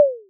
Pop Bubble
Pop Bubble is a free ui/ux sound effect available for download in MP3 format.
034_pop_bubble.mp3